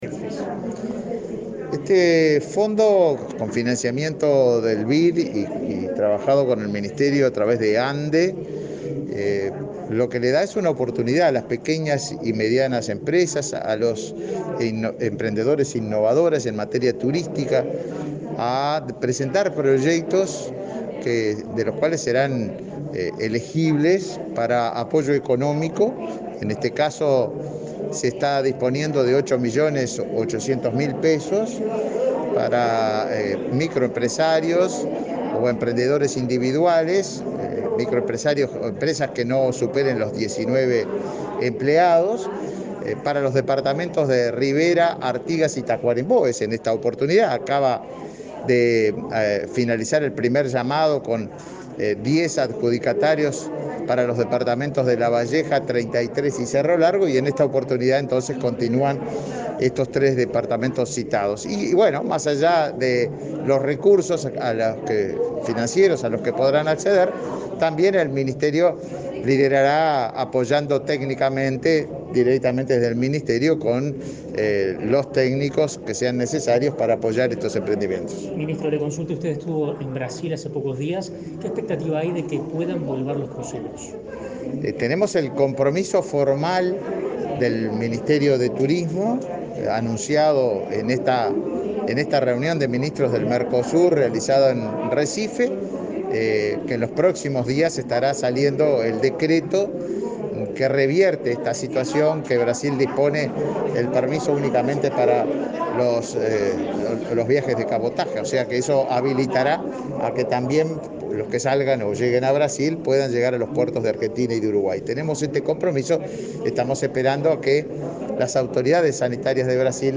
Declaraciones a la prensa del ministro de Turismo, Tabaré Viera
El ministro de Turismo, Tabaré Viera, dialogó con la prensa, luego de presentar, junto con la presidenta de la Agencia Nacional de Desarrollo (ANDE), Carmen Sánchez, el segundo llamado del fondo concursable para financiar empresas turísticas y emprendimientos que focalicen sus propuestas en los departamentos de Artigas, Rivera y Tacuarembó.